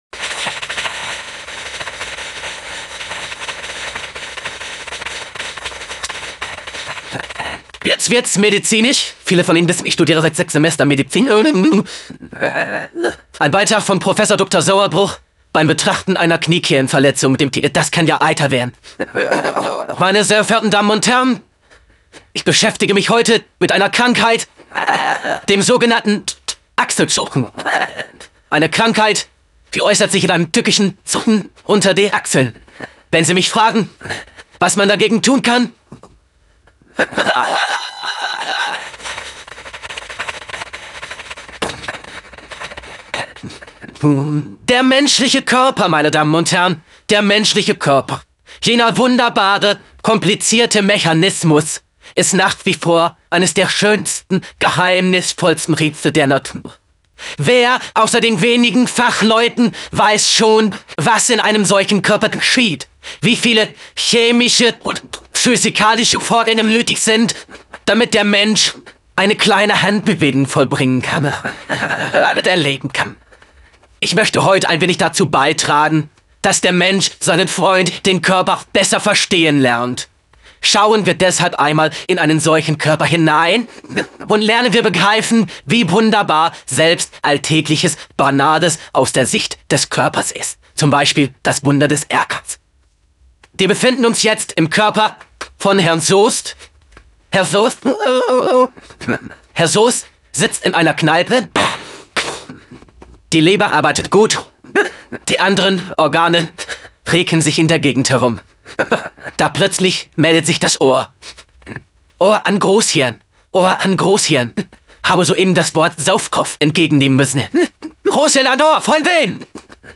Otto Walkes Der Menschliche Körper_Joey Wheeler_DE_voice.wav